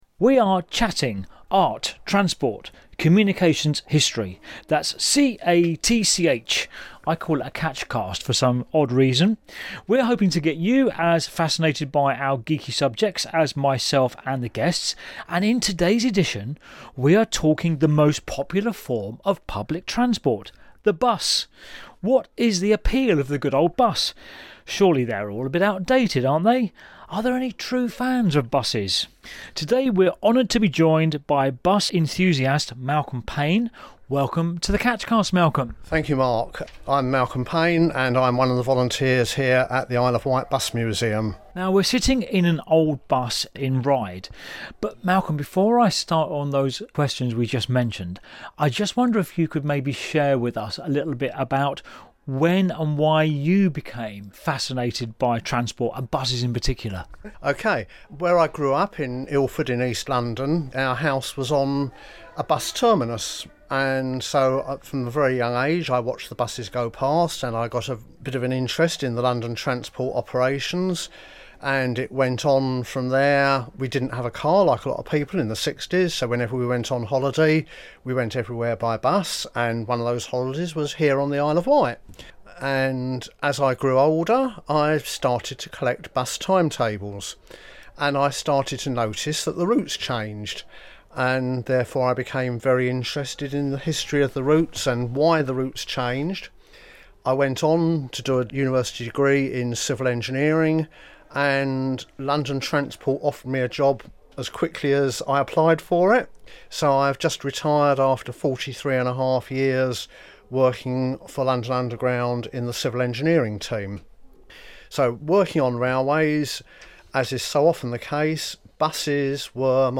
To celebrate Rydabus an open air, round the island display of vintage buses, we go on location to the Isle of Wight Bus and Coach Museum to climb aboard!